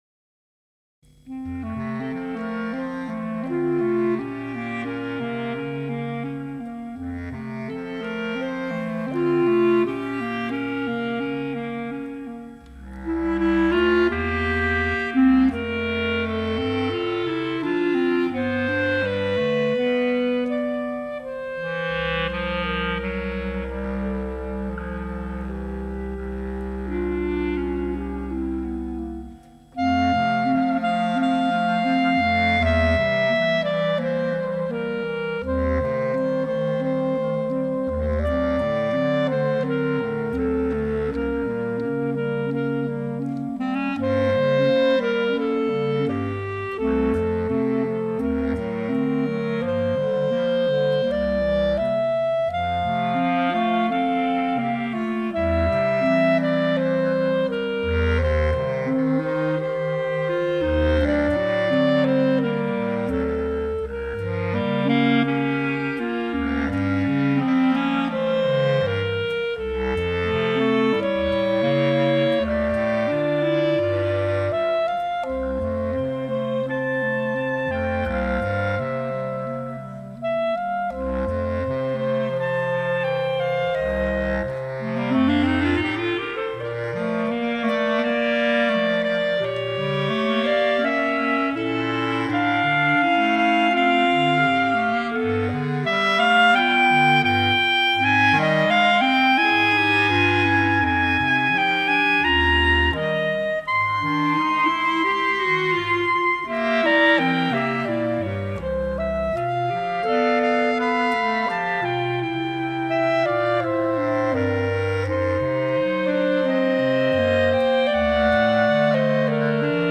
This romantically style, quasi-Classical composition